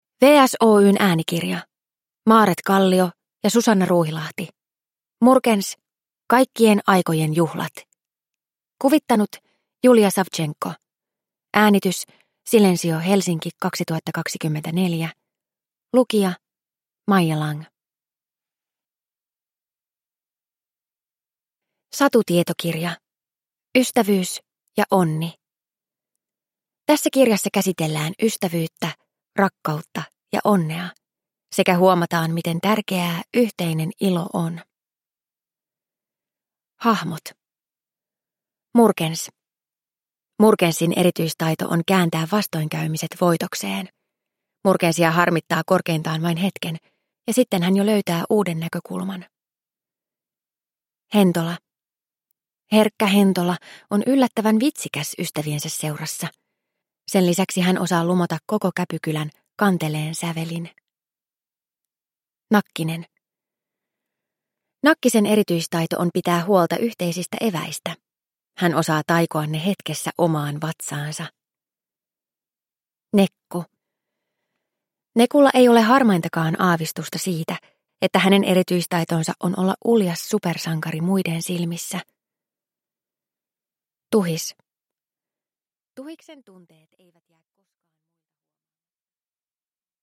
Murkens: Kaikkien aikojen juhlat (ljudbok) av Maaret Kallio